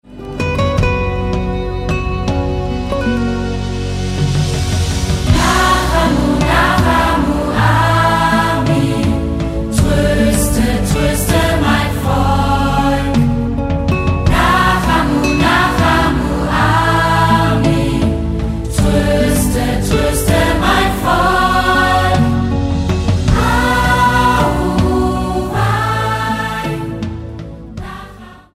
Musical-CD
14 Lieder und kurze Theaterszenen